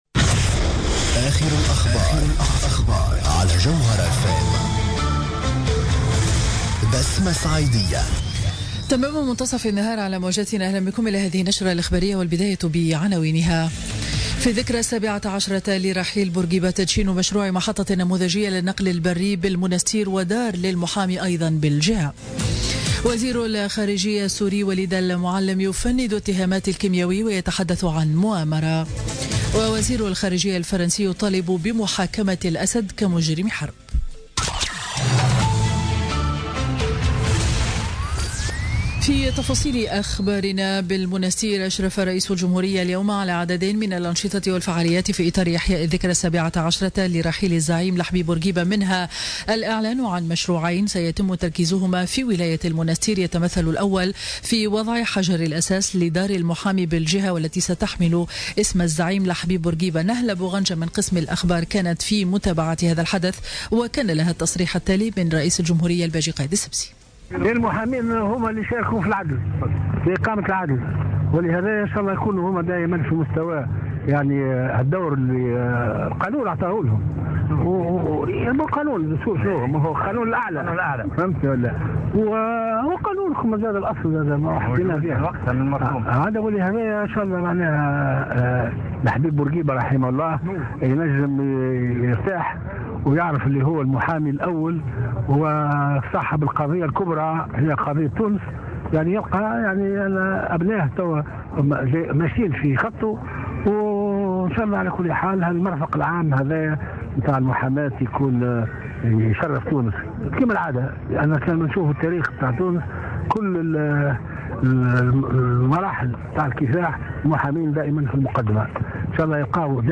نشرة أخبار منتصف النهار ليوم الخميس 6 أفريل 2017